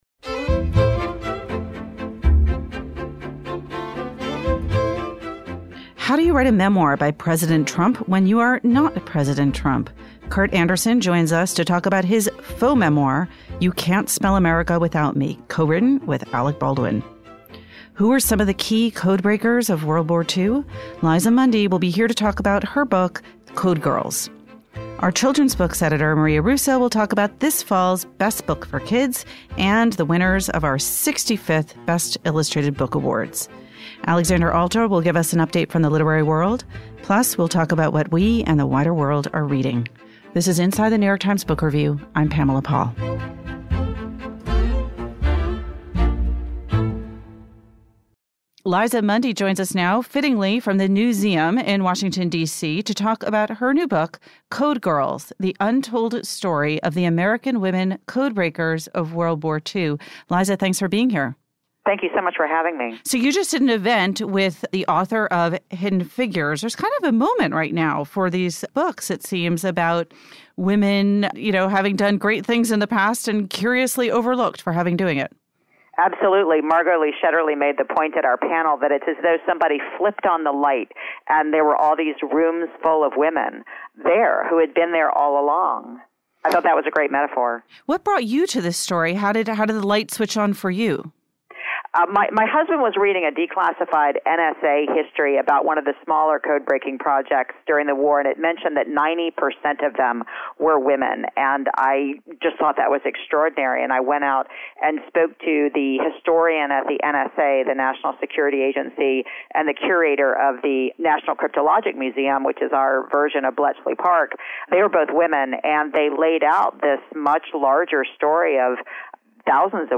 The New York Times Book Review podcast – interview